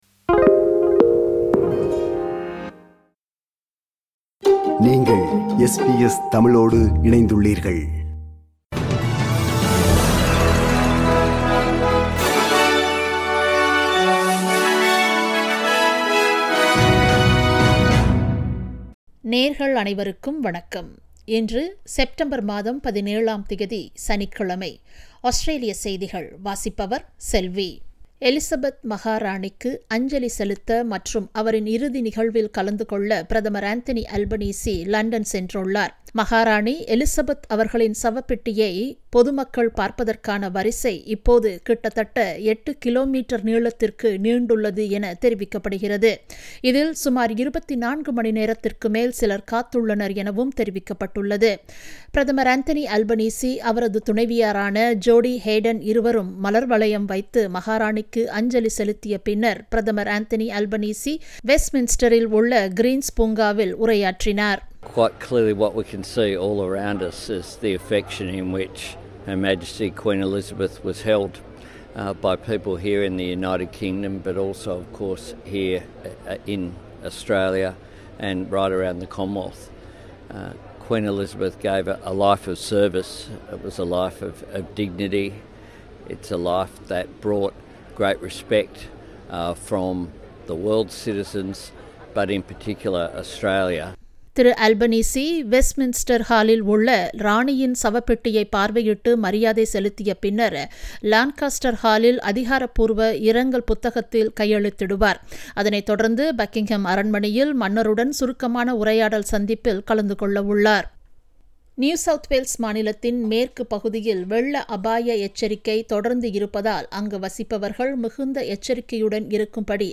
Australian news bulletin for Saturday 17 September 2022.